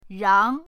rang2.mp3